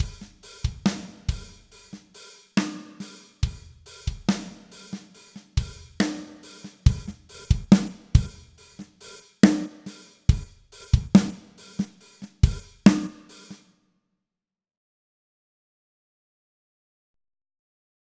hier schlagzeug einmal ohne und einmal mit transient splitter plus eq und sättigung im attackbereich
(lautstärkeverhältnisse habe ich nachher noch angeglichen):
Ich schreibe das weil ich mir dein Soundbeispiel angehört habe und mir die Transienten gefallen, aber die Hihat irgendwo leblos klingt.
transientsplitter.mp3